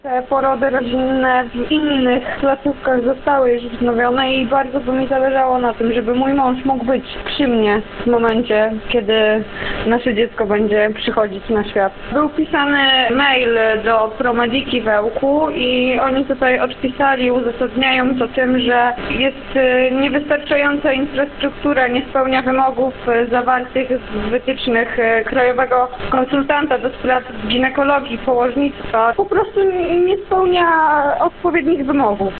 – Termin rozwiązania mam wyznaczony na końcówkę lipca, a w tej chwili nie wiemy, jak to będzie wyglądało – mówi słuchaczka.